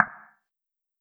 back-button-click.wav